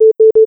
3beepvirus.wav